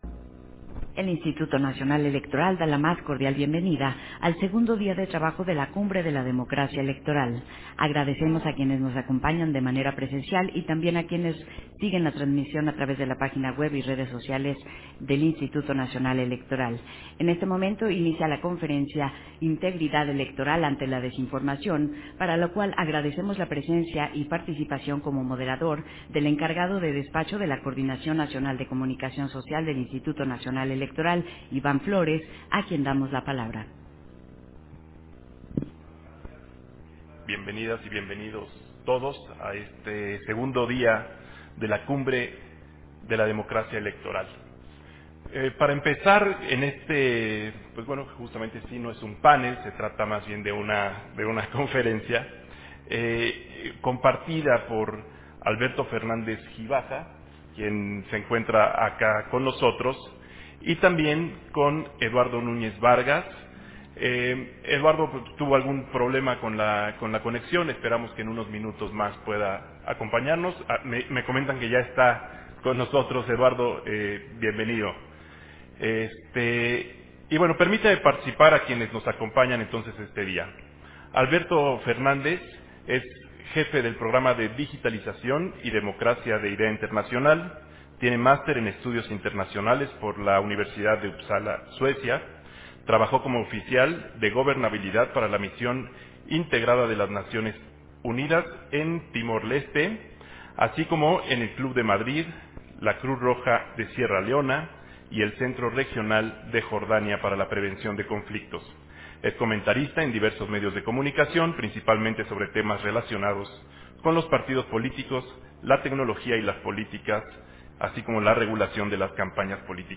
051223_AUDIO_CONFERENCIA_INTEGRIDAD-ELECTORAL-ANTE-LA-DESINFORMACIÓN
Versión estenográfica de la conferencia, Integridad electoral ante la desinformación, en el marco de la II Cumbre de la Democracia Electoral